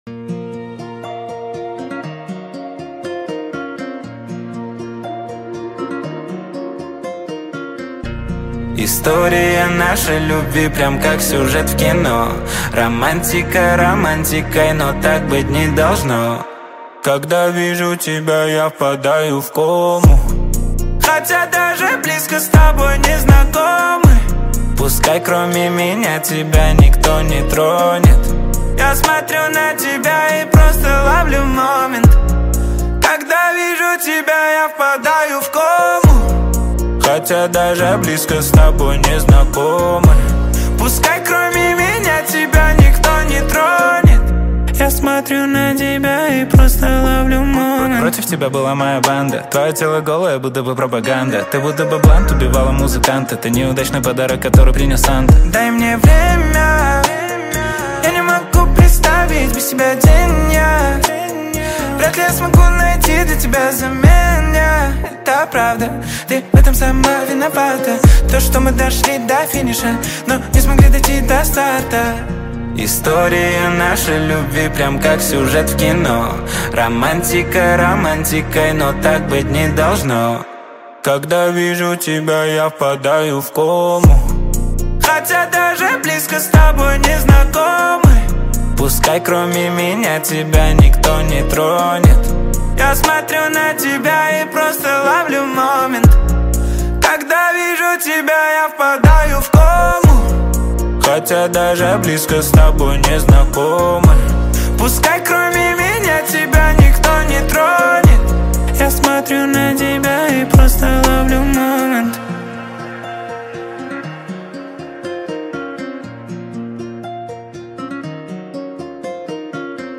Казахские песни